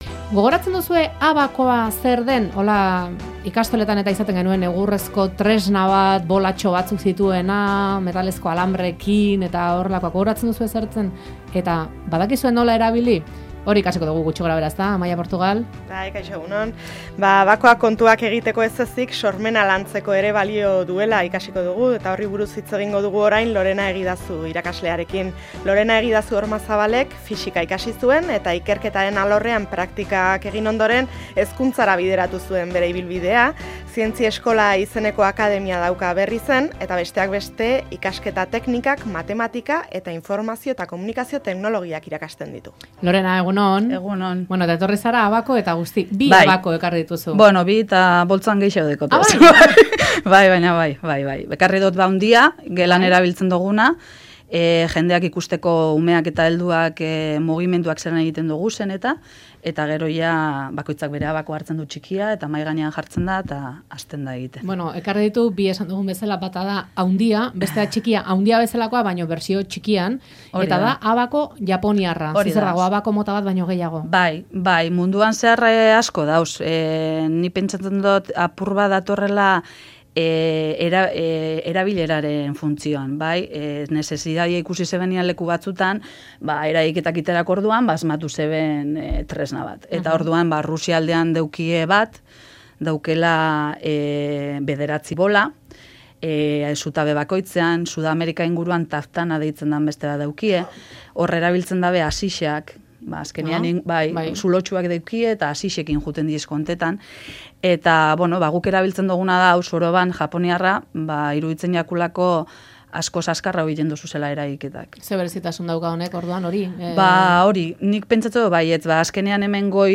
Faktoriako estudioan izan da, abakoarekin ariketak nola egiten diren erakusteko. Abakoaren erabilerak sormena pizten duela azaldu du.